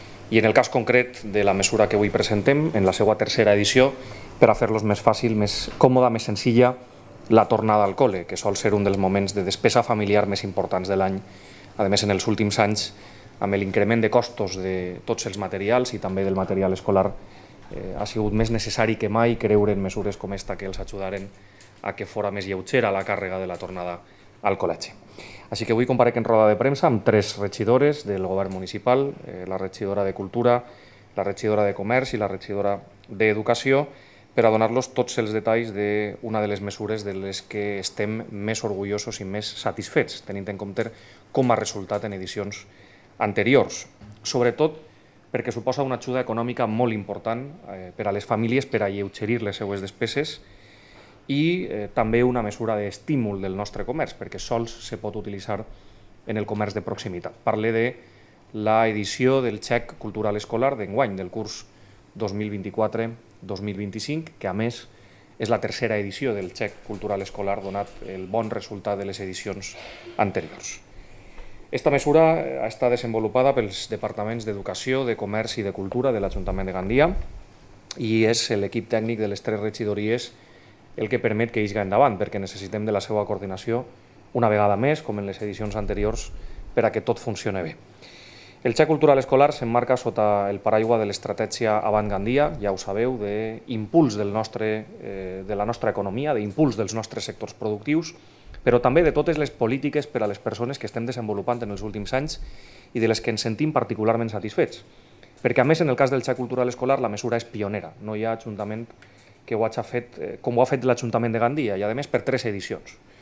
alcalde de Gandia (audio)